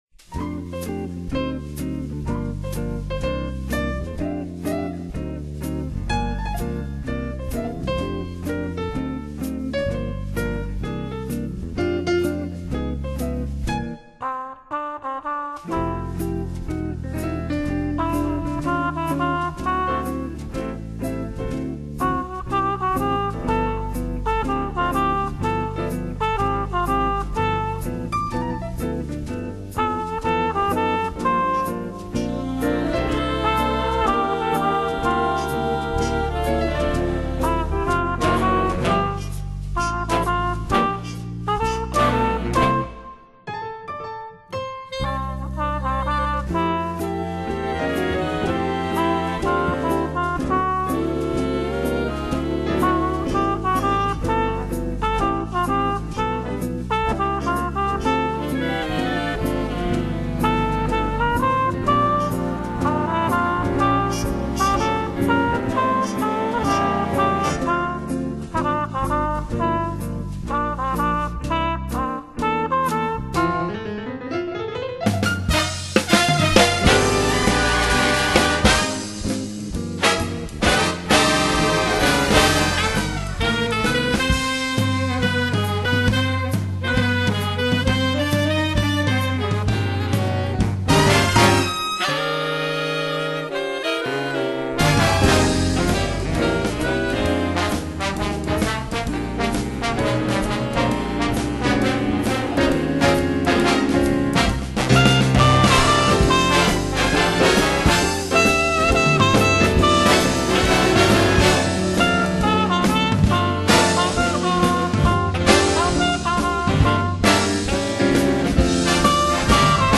Genre: Jazz / Big Band